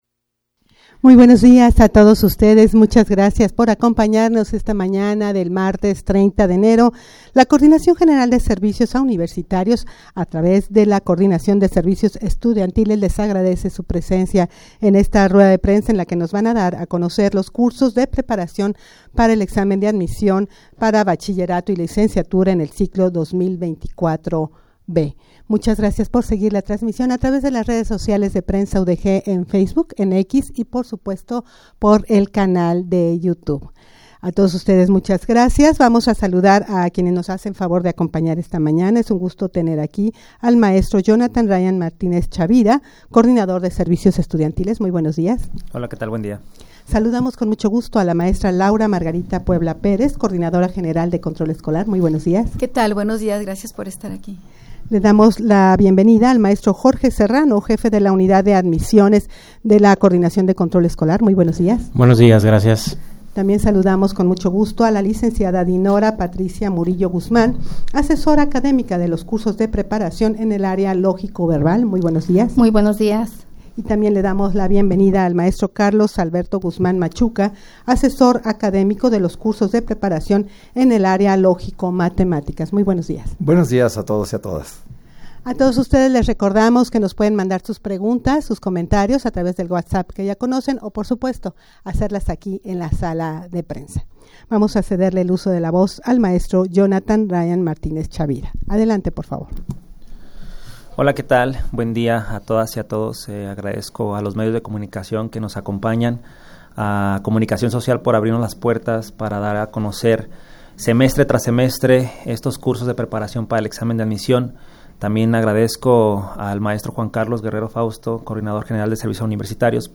rueda-de-prensa-para-dar-a-conocer-los-cursos-udg-de-preparacion-para-el-examen-de-admision.mp3